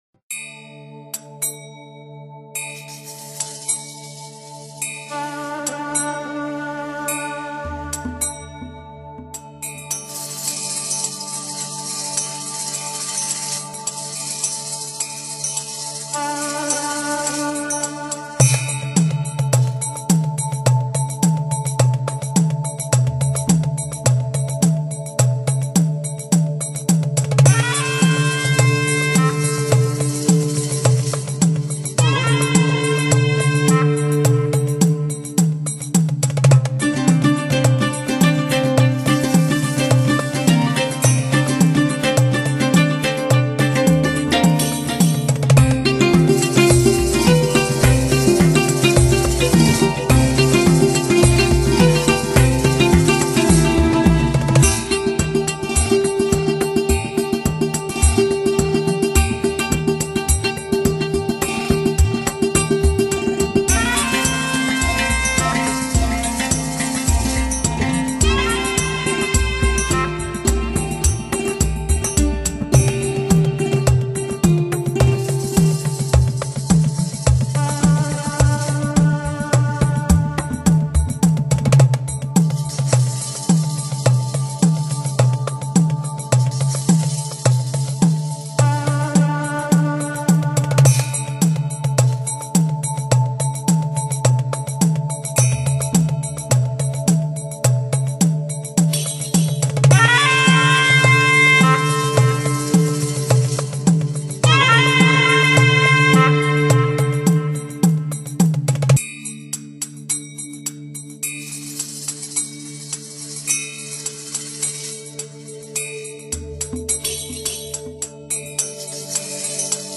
Genre: Downtempo, Lounge, Chillout